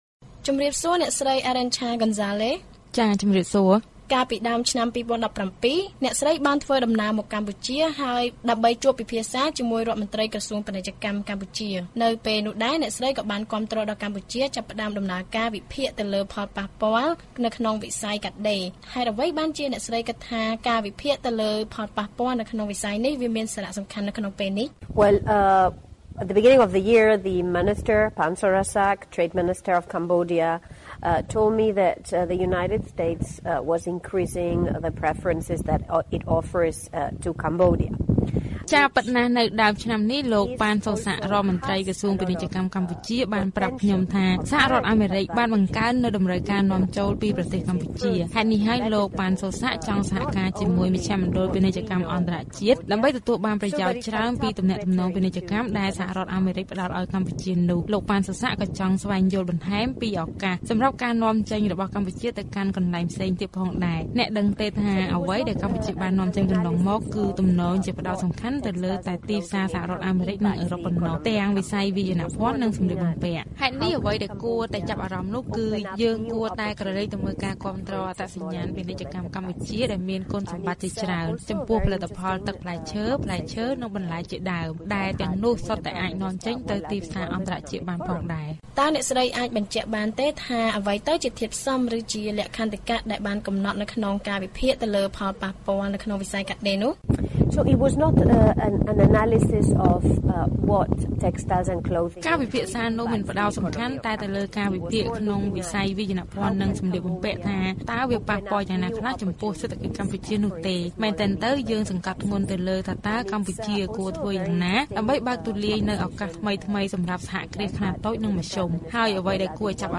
បទសម្ភាសន៍ VOA៖ អ្នកជំនាញស្នើកម្ពុជាពង្រីកទីផ្សារក្នុងតំបន់ក្រៅពីអឺរ៉ុបនិងសហរដ្ឋអាមេរិក